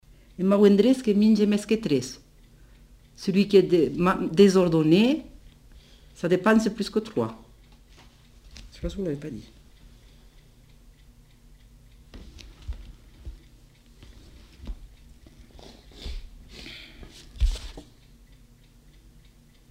Lieu : Cathervielle
Genre : forme brève
Type de voix : voix de femme
Production du son : récité
Classification : proverbe-dicton